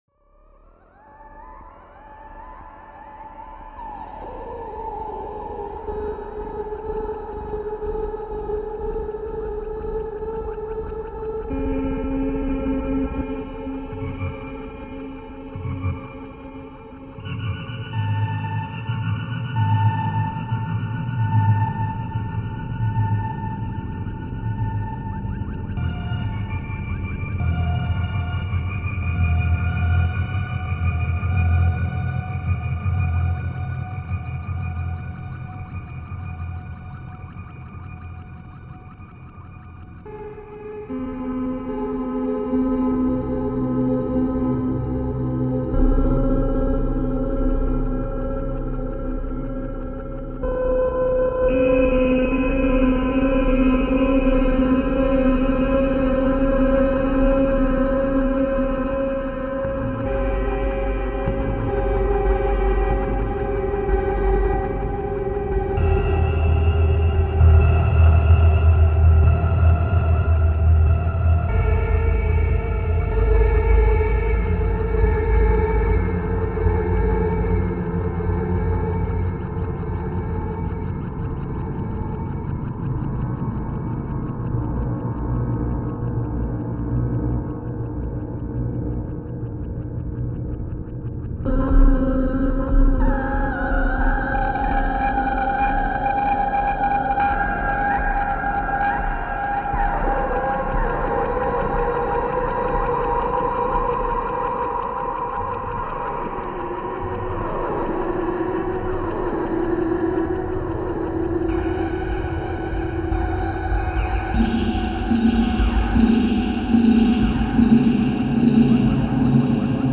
Hear is a track of just my CircuitBent Merlin CV sequencer VCO thingy as source from earlier today...
modded it for sending Pitch CV from Music Machine game sequencer and receiving CV for using it as VCO...
way more strange experimental with almost fem vox results at times...
MerlinSourcerySequencer.mp3